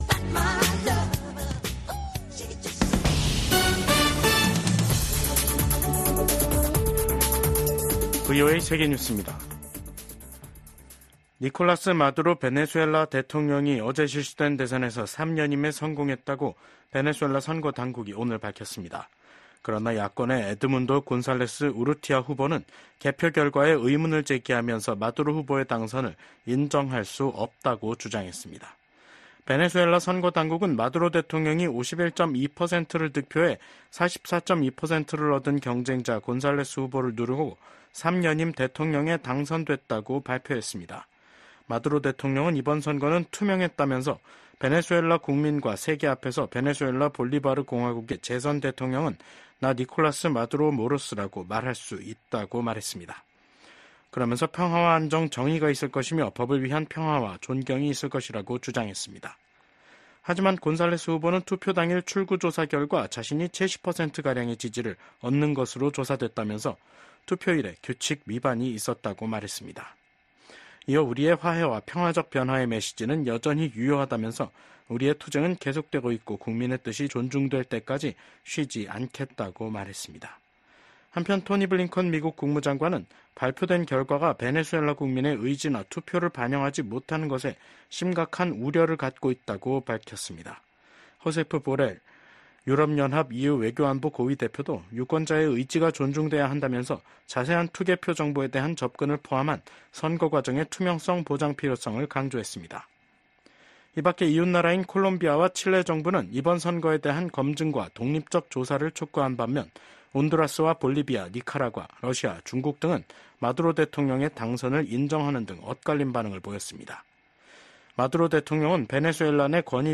VOA 한국어 간판 뉴스 프로그램 '뉴스 투데이', 2024년 7월 29일 3부 방송입니다. 미국과 한국, 일본이 안보 협력을 제도화하는 문서에 서명했습니다.